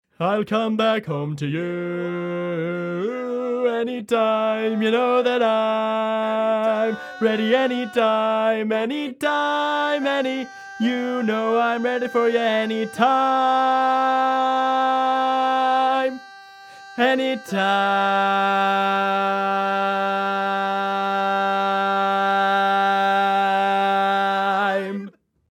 How many parts: 4
Type: Barbershop
Learning tracks sung by